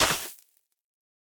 Minecraft Version Minecraft Version latest Latest Release | Latest Snapshot latest / assets / minecraft / sounds / block / suspicious_sand / step3.ogg Compare With Compare With Latest Release | Latest Snapshot
step3.ogg